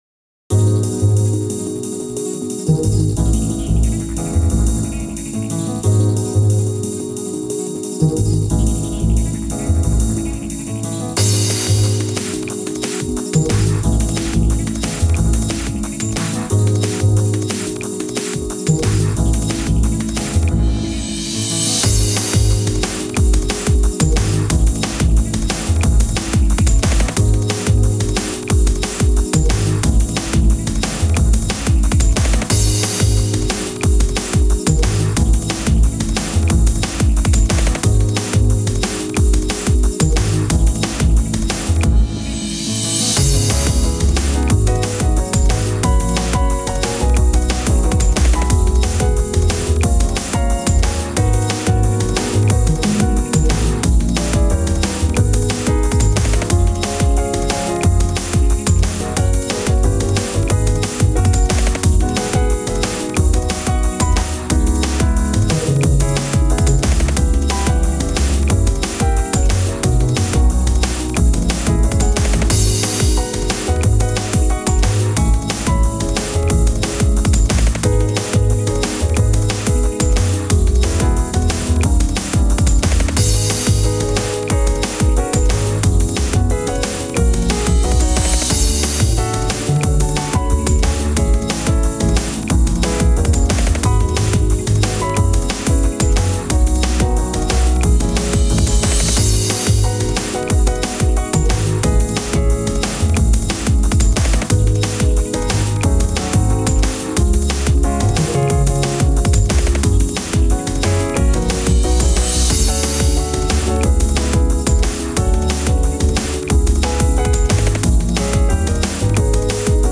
Jazzy Drum'n Bass